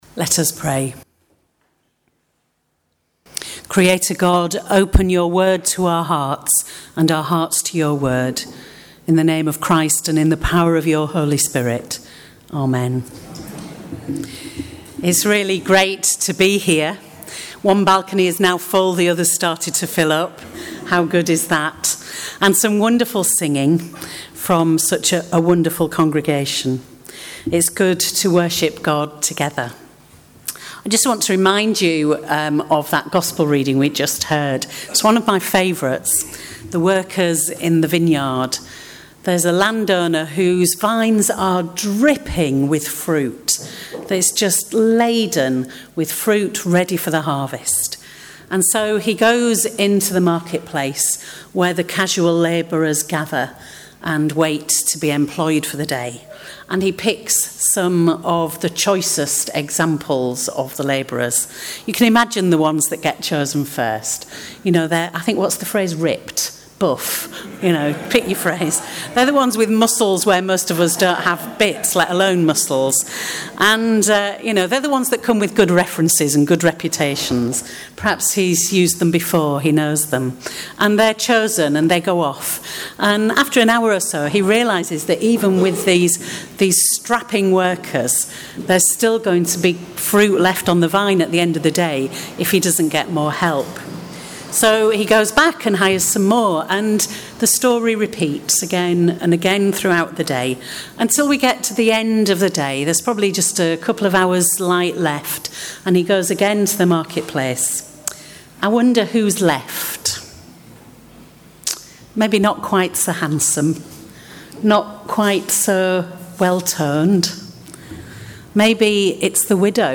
“Workers in the Vineyard” – Circuit Welcome and Commissioning Service
7th Oct It was a wonderful presentation of a sermon on a passage that I in the past found hard to come to terms with being a trade union member.